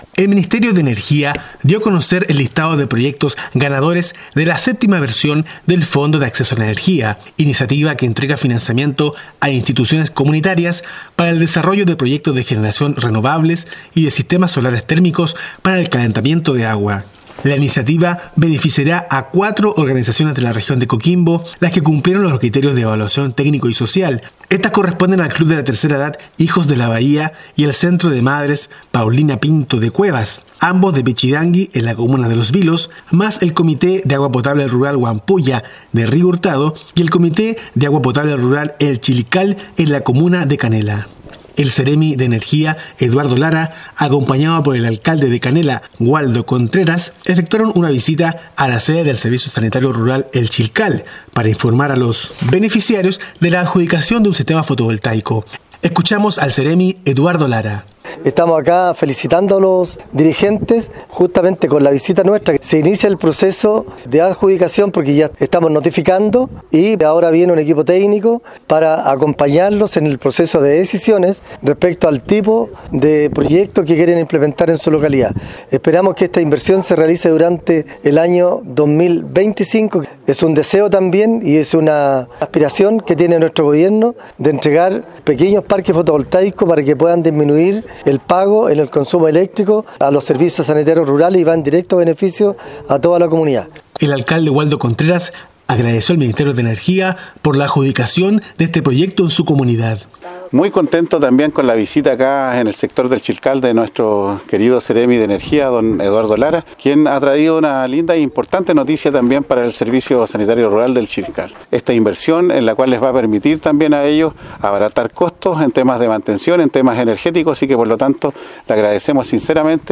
DESPACHO RADIAL